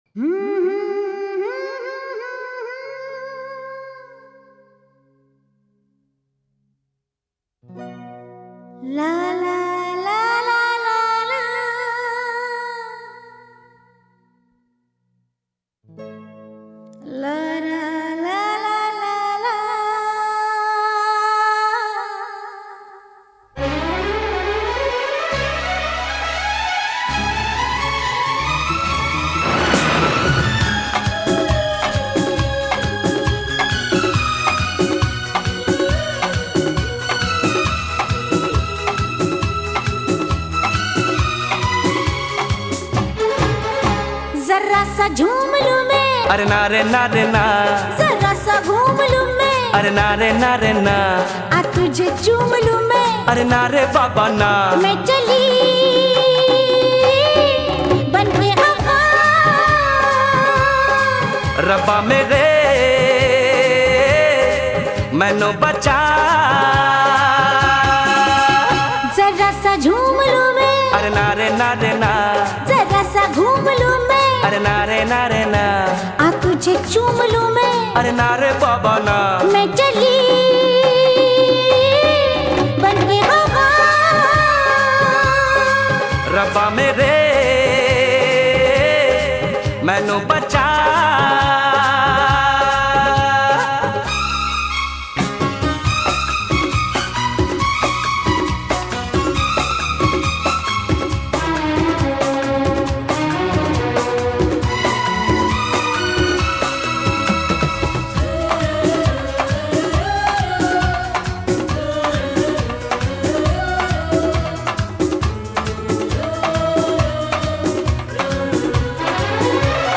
Carpeta: musica hindu mp3